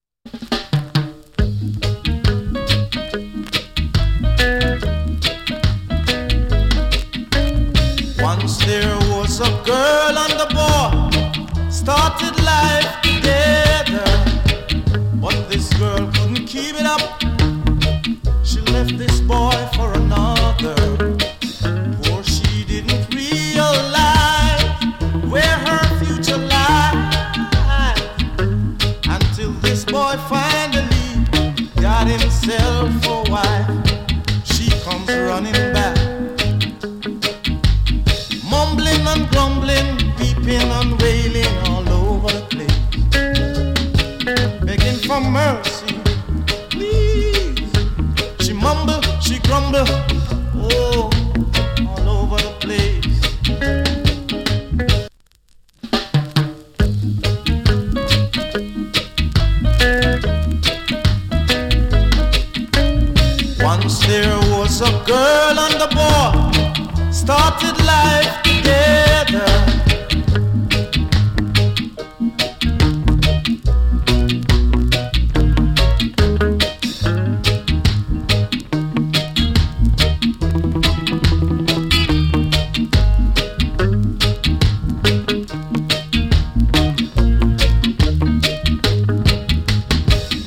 わずかにチリ、ジリノイズ有り。
77年 KILLER ROOTS VOCAL !